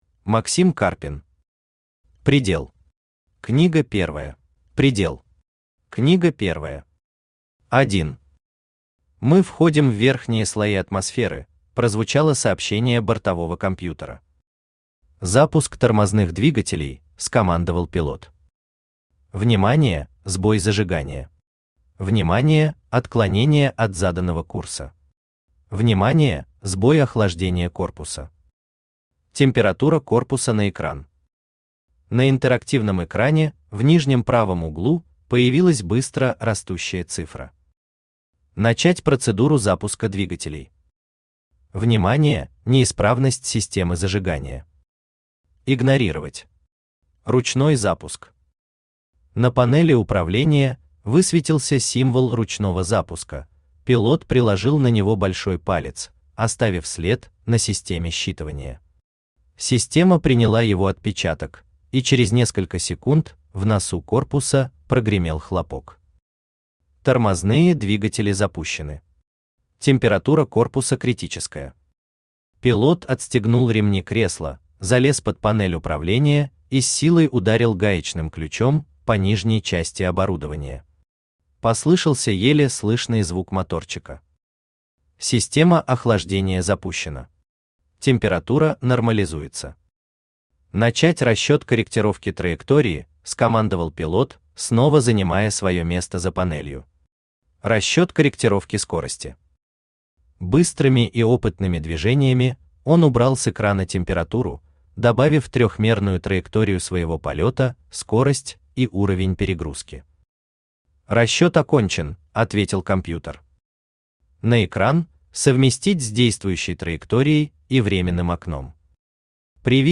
Аудиокнига Предел. Книга первая | Библиотека аудиокниг
Книга первая Автор Максим Сергеевич Карпин Читает аудиокнигу Авточтец ЛитРес.